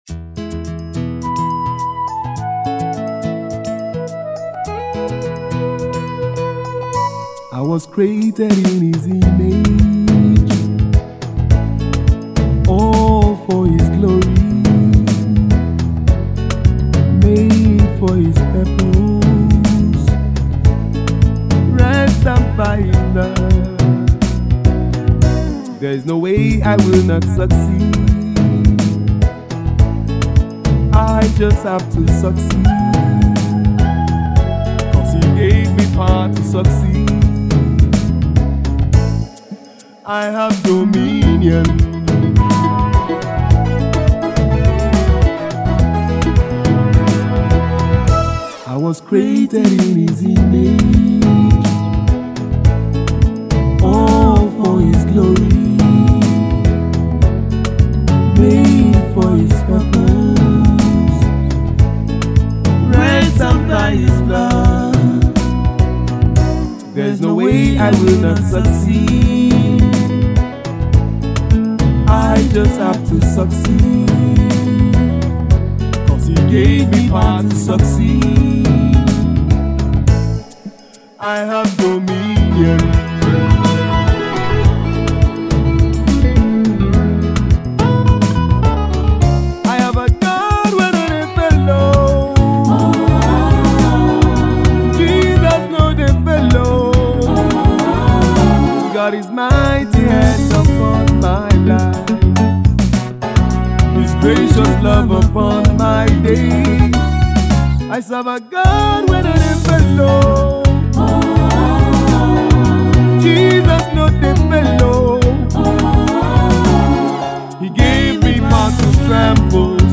praise single
uses Afrocentric music elements and diction